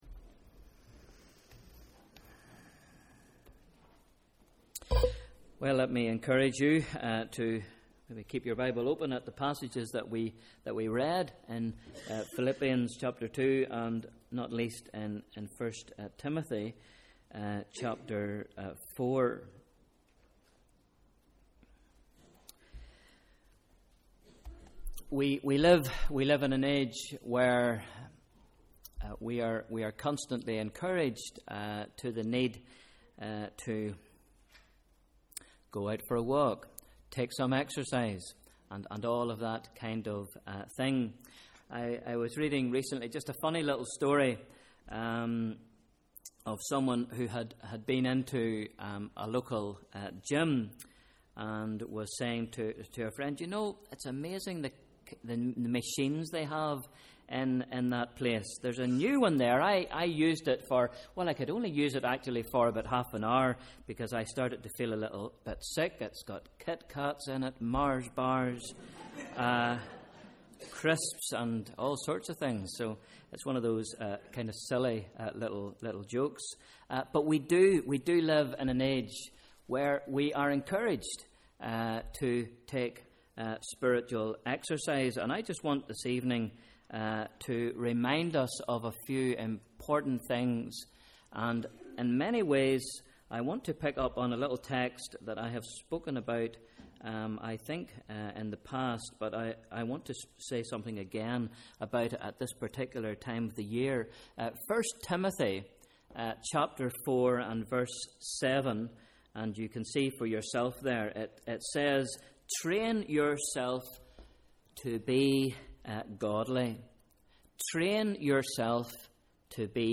Evening Service: Sunday 22nd September 2013 / Bible Reading: 1 Timothy 4